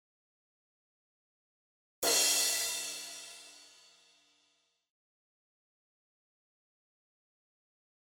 Rock Standard Kit 1のC＃2の場所に入っているクラッシュシンバルの音を使います。
あのドラムセットの高い位置に君臨する某アニメの音柱もびっくりするぐらいド派手な音が鳴るやつですね。
爽快な音ですね！！